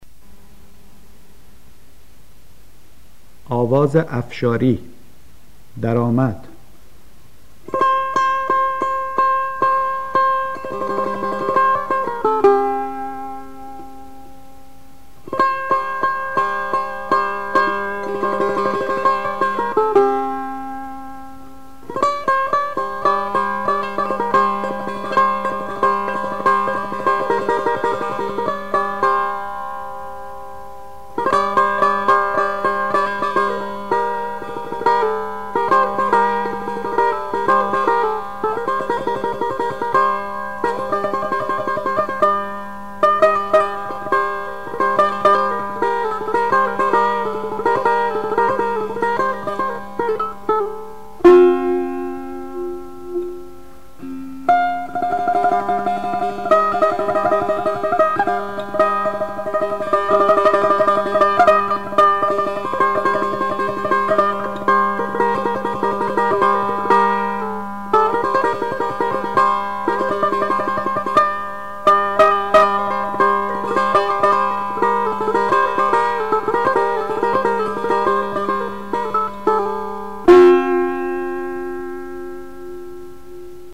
آواز افشاری ردیف میرزا عبدالله سه تار
در این روایت، درآمد آواز افشاری با تأکید بر درجه دوم شور به عنوان نت ایست و درجه چهارم به عنوان نت شاهد ارائه می‌شود. استاد طلایی در اجرای خود با ساز سه تار، به خوبی توانسته است همانندی‌های این آواز را با دستگاه‌های نوا و ماهور به نمایش گذارد.